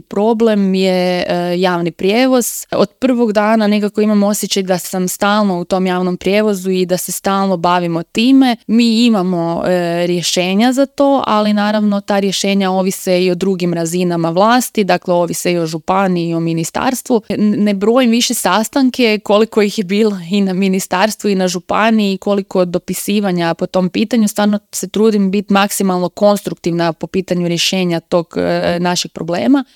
ZAGREB - U intervjuu Media servisa povodom rođendana grada Samobora gostovala je gradonačelnica Petra Škrobot.